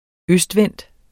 Udtale [ -ˌvεnˀd ]